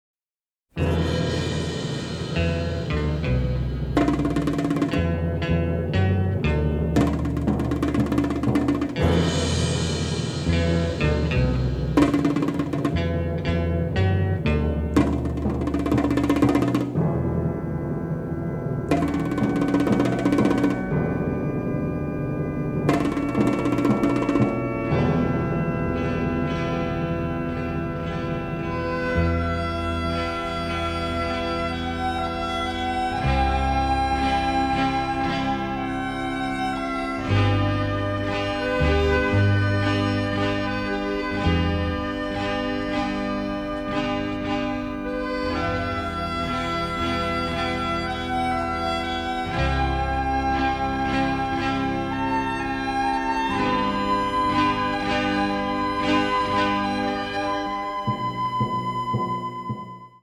western score